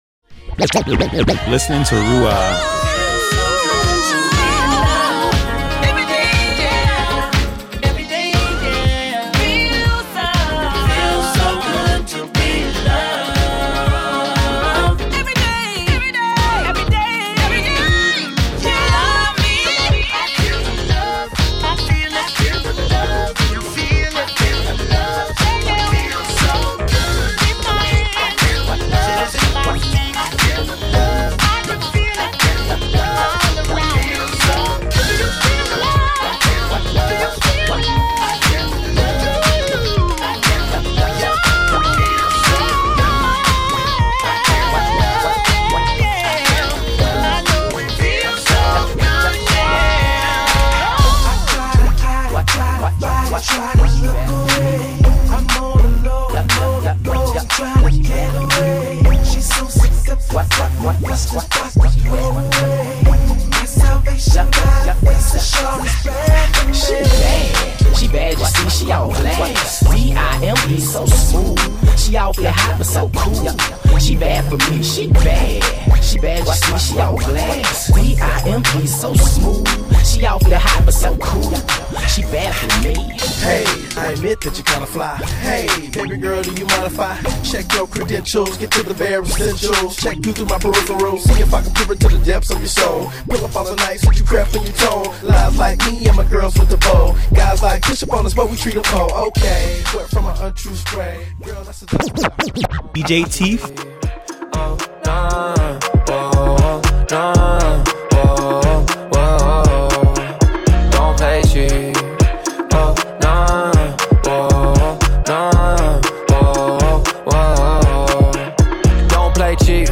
Urban-Adult Contemporary Mix Demo #1